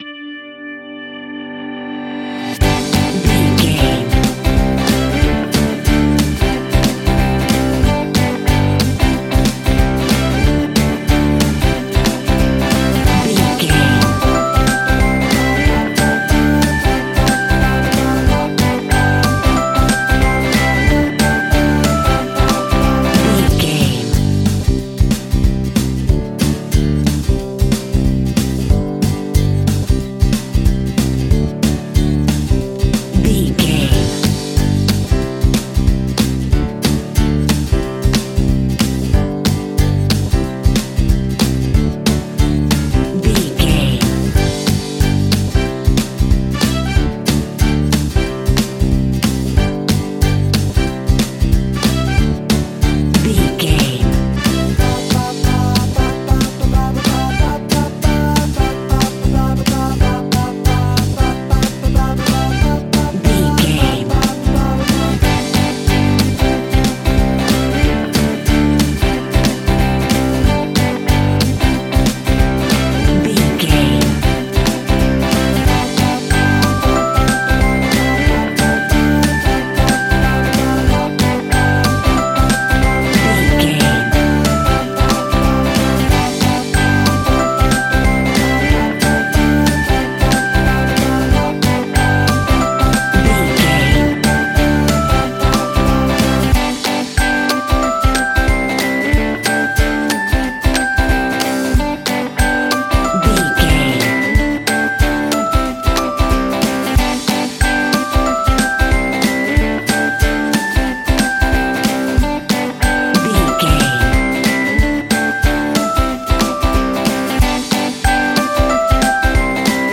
Ionian/Major
pop
cheesy
electro pop
pop rock
synth pop
pop rock instrumentals
happy
peppy
upbeat
bright
bouncy
drums
bass guitar
electric guitar
keyboards
hammond organ
acoustic guitar
percussion